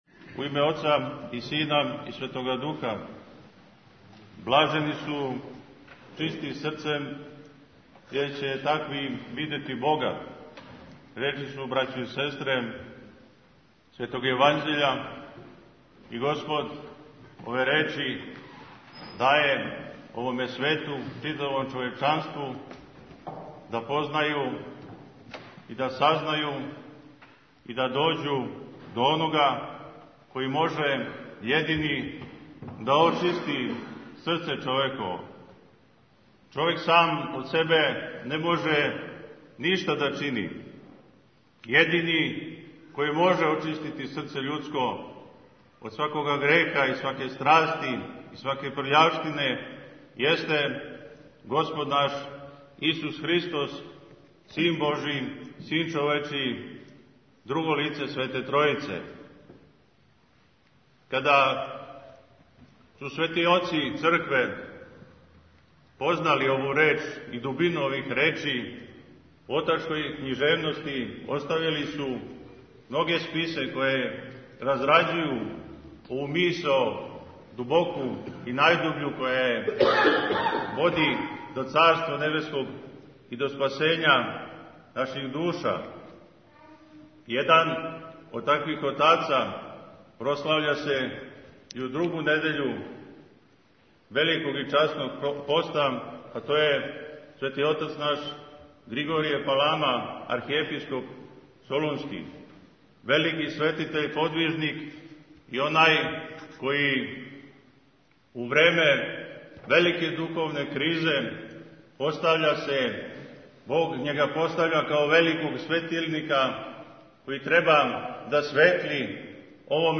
Беседа
на Светој Литургији коју је служио у манастиру Савина у другу недељу Великог поста